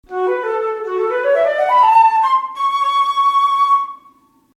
Querflöte
Die Querflöte ist elegant, lieblich, zauberhaft, zart, klar, glitzernd, silbrig, engelhaft, brllant, pfefffend, trillernd, tänzelnd, zwitschernd, schwungvoll, sanft, beruhigend, meditativ, flatternd, festlich, leicht! Die Querflöte ghört zur Familie der Holzblasinstrumente.
Der Ton wird erzeugt, in dem man durch einen schmalen Lippenspalt einen kontrollilerten Luftstrom an die gegenüberliegende Mundlochkante bläst.